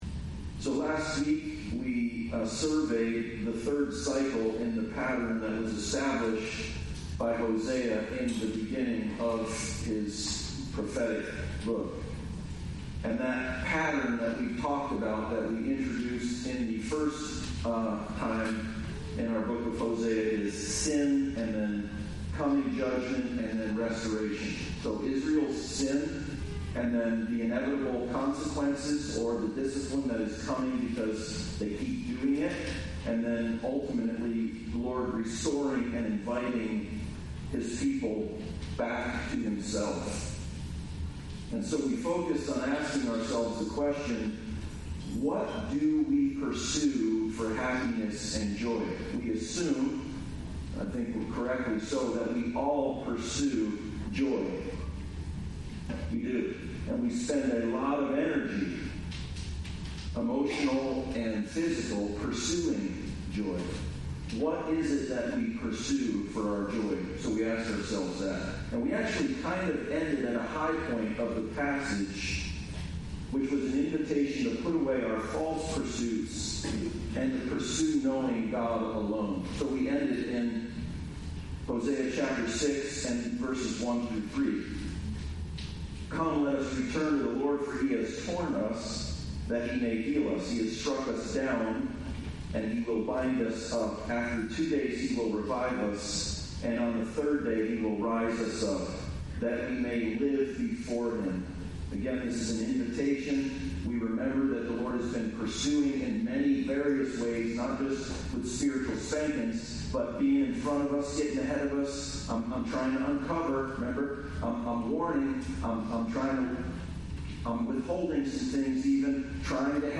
Generous Grace Passage: Hosea 6:7-10:15 Service Type: Sunday Service « Generous Grace